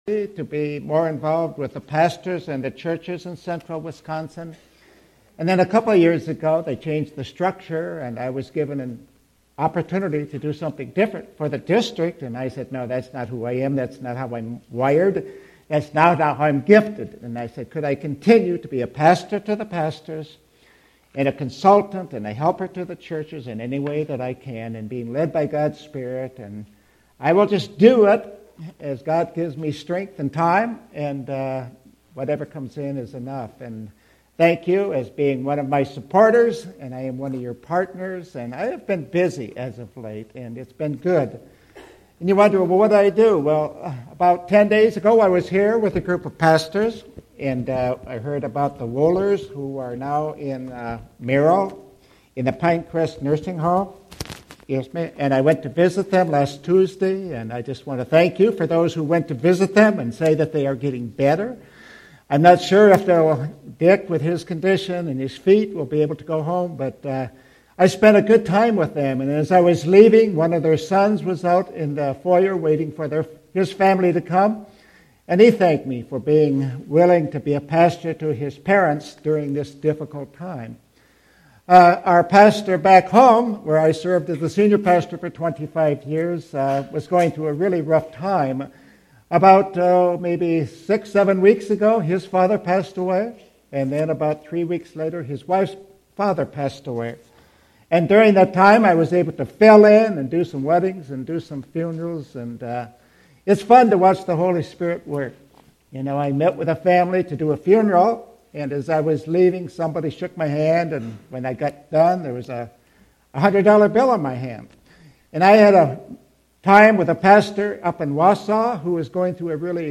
sermon61415.mp3